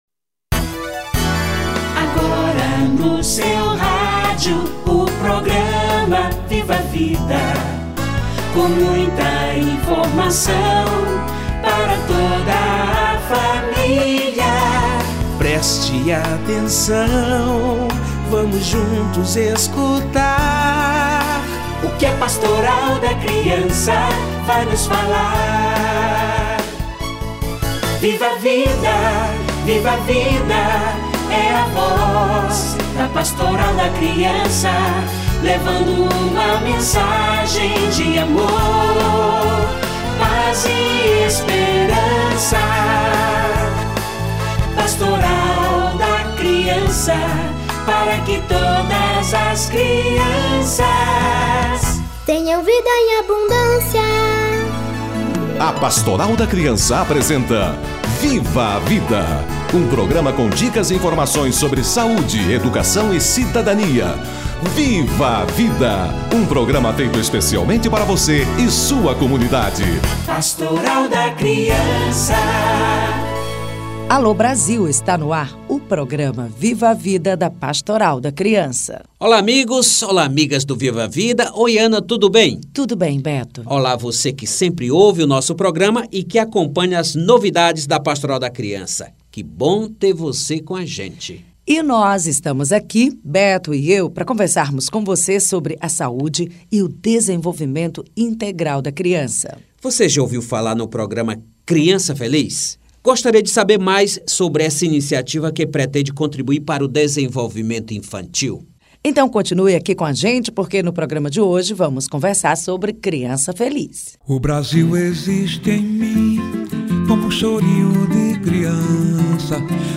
Criança Feliz - Entrevista